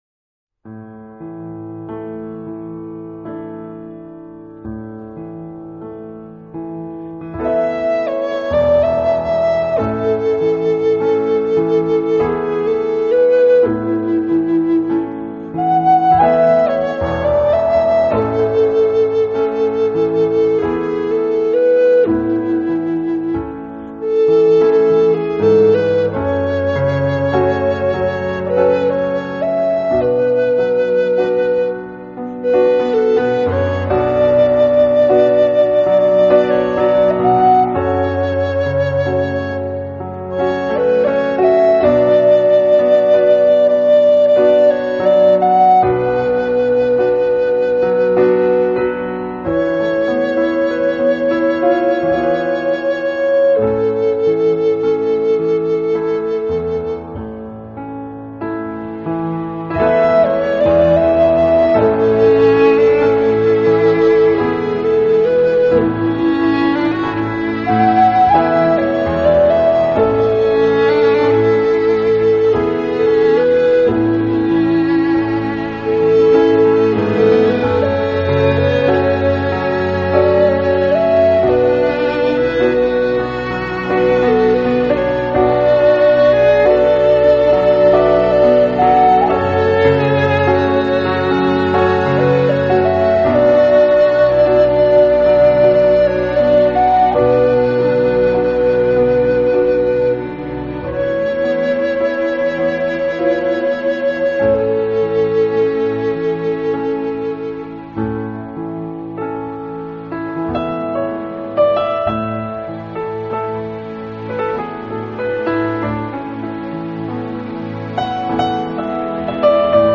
音乐类型:New Age / Native American / International